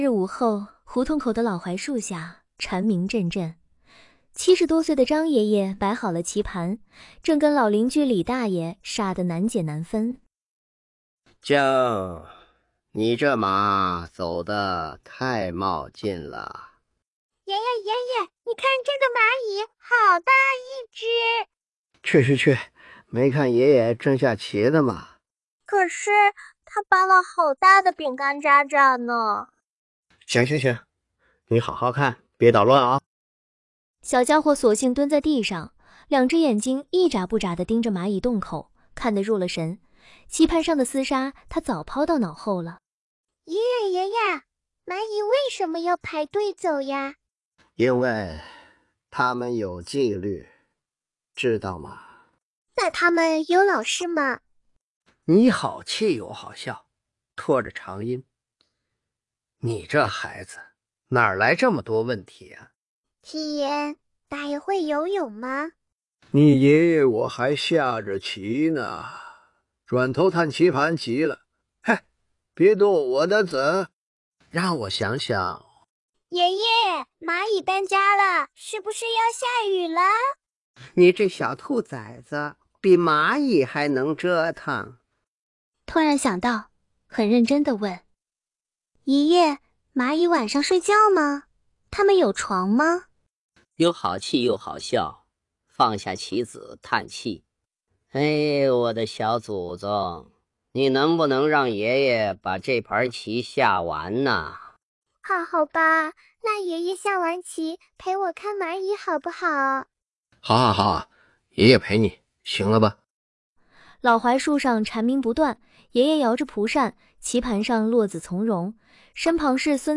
不过音频中，有出现主人公边说边自己旁白的情况，且爷爷的声音特点并没有在整个说话环节都保持一致，中间会突然背离需求的“嗓门哑、拖长音”，语气突然变快等。